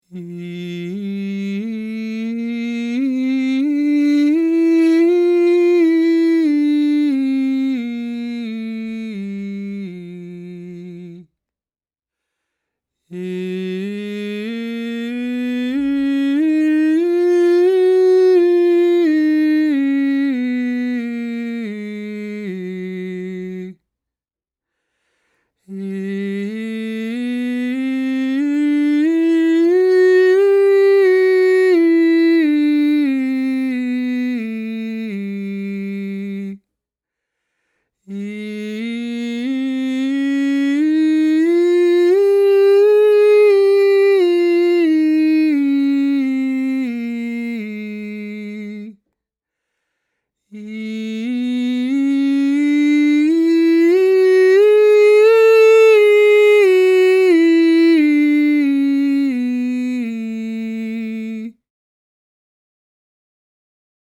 Stemtesten Bereik
met Stemtest 1 Man
Stemtest-1-Man.mp3